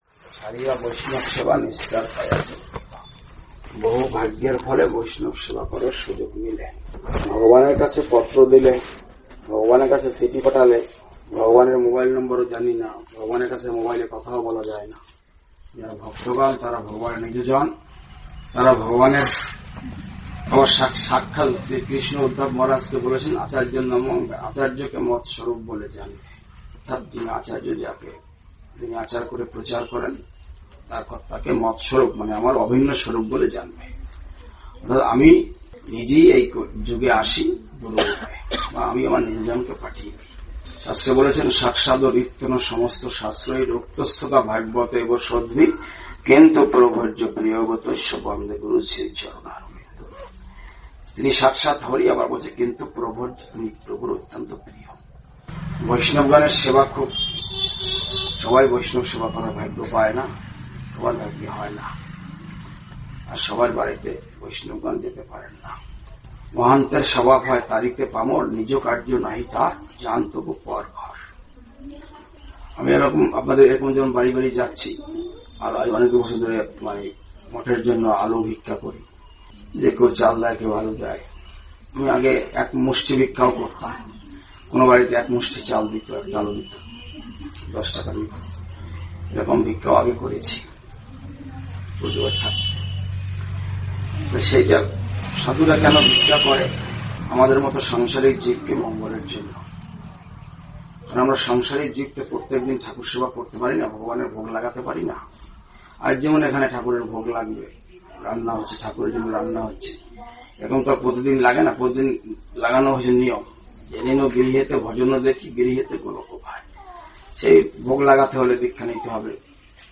Tarakeshwar area, 10 February 2021 (noon), part 1